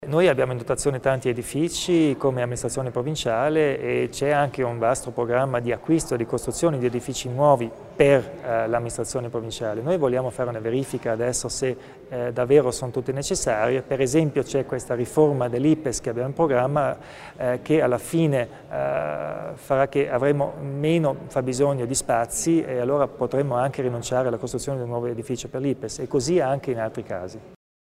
Il Presidente Kompatscher illustra gli obiettivi in tema di infrastrutture